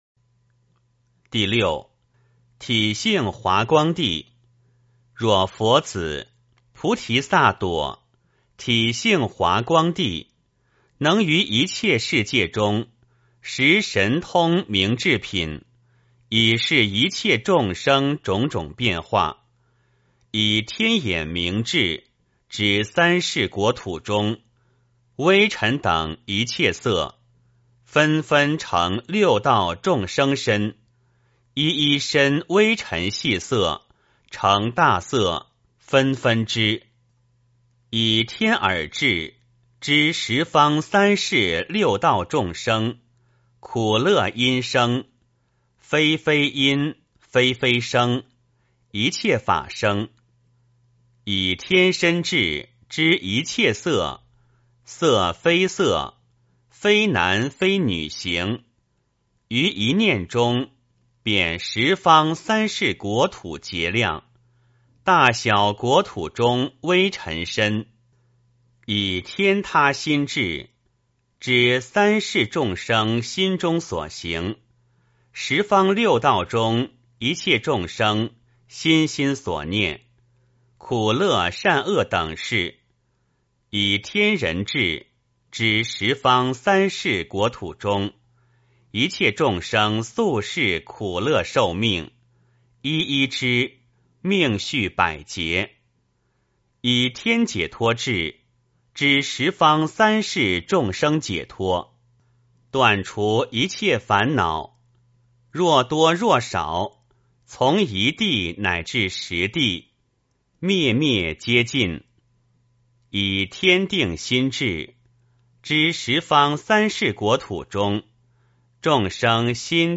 梵网经-十地-体性华光地 诵经 梵网经-十地-体性华光地--未知 点我： 标签: 佛音 诵经 佛教音乐 返回列表 上一篇： 梵网经-十地-体性尔焰地 下一篇： 梵网经-十地-体性华严地 相关文章 职场11跟压力说再见--佛音大家唱 职场11跟压力说再见--佛音大家唱...